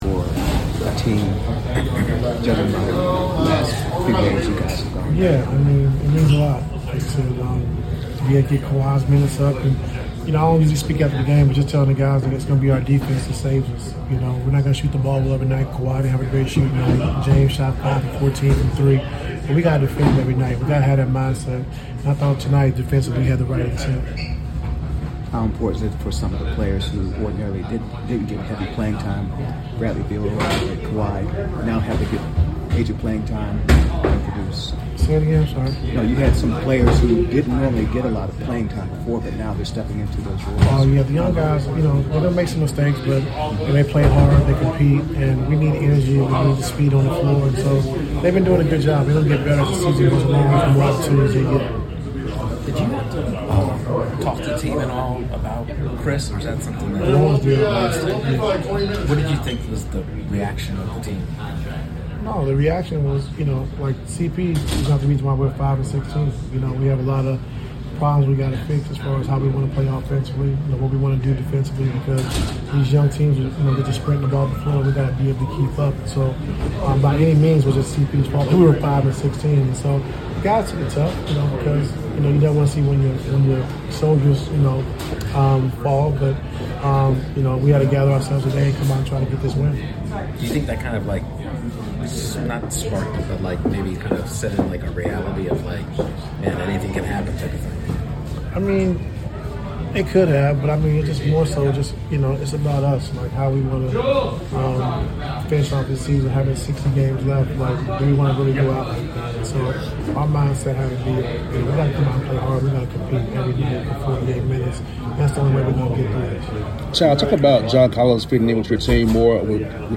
Los Angeles Clippers Coach Tyronn Lue Postgame Interview after defeating the Atlanta Hawks at State Farm Arena.